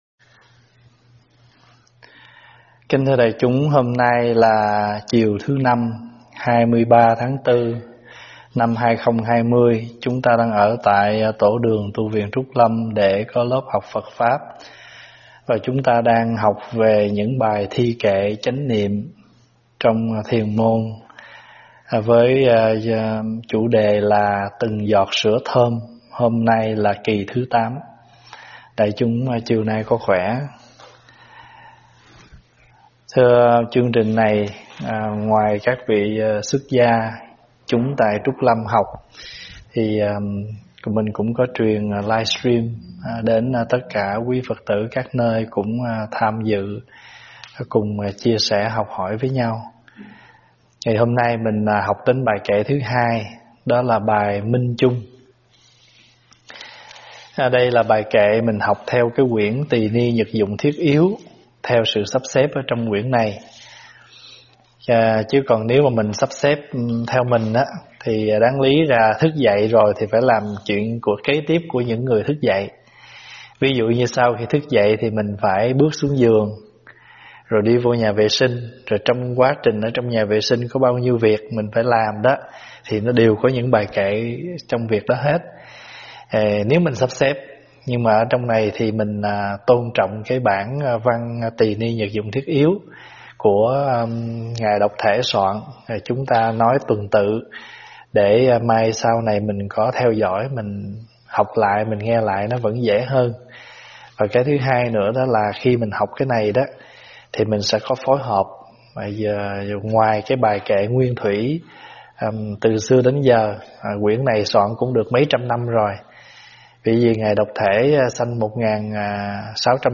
Mp3 thuyết pháp Minh chung
giảng trực tuyến tại tv Trúc Lâm